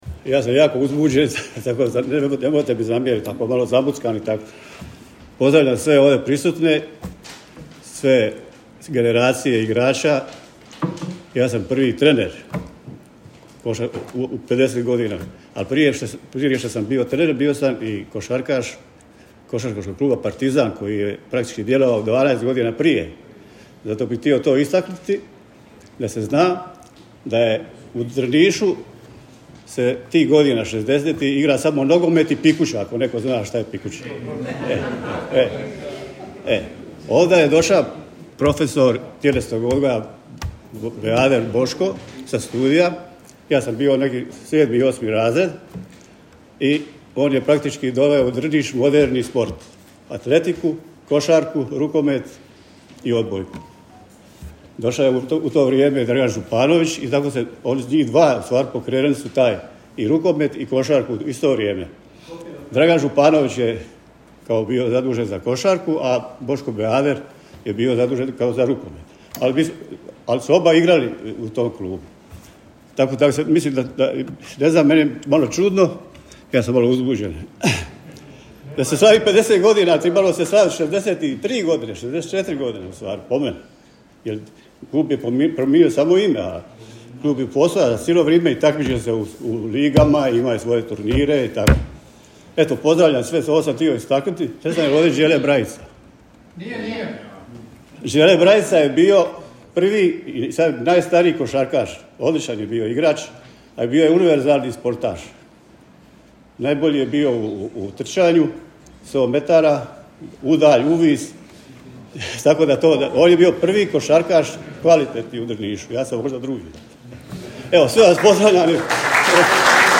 u svom emotivnom obraćanju